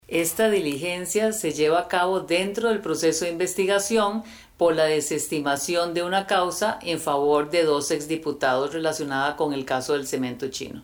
La Fiscal General, Emilia Navas, explicó la causa de investigación con la cual se relaciona la diligencia que realizó la Fiscalía.